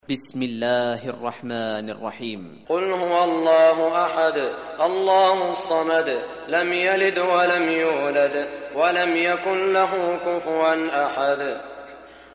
Surah Ikhlas Recitation by Sheikh Saud Shuraim
Surah Ikhlas, listen or play online mp3 tilawat / recitation in Arabic in the beautiful voice of Sheikh Saud al Shuraim.